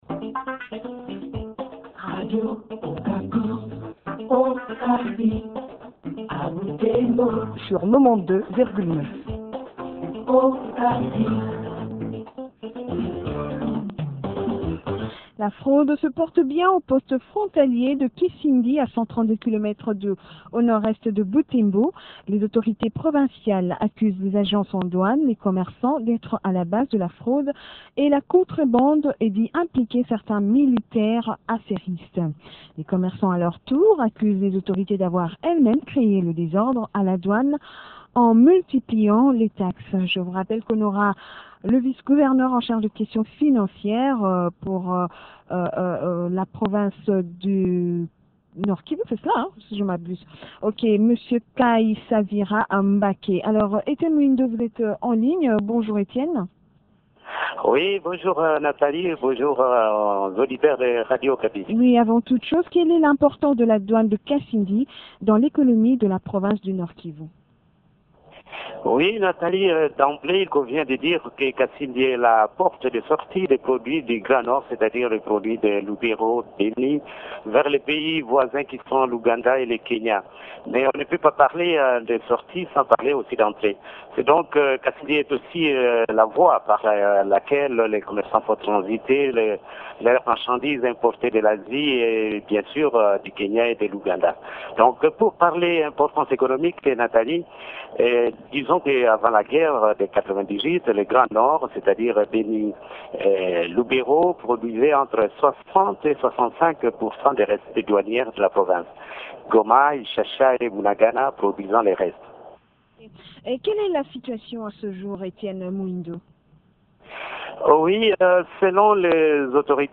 reçoit Kaisavira Mbake, vice gouverneur du Nord Kivu en charge des questions financières.